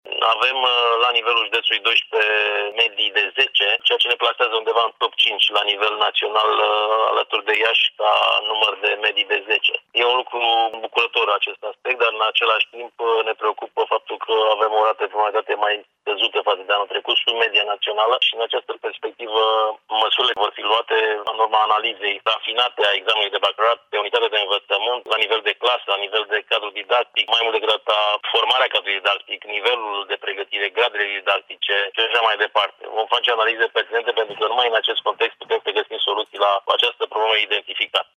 Acest lucru care va fi analizat atent, în următoarea perioadă, susține șeful Inspectoratului Școlar Județean Timiș, Marin Popescu.